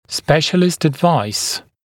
[‘speʃəlɪst əd’vaɪs][‘спэшэлист эд’вайс]консультация специалиста, совет специалиста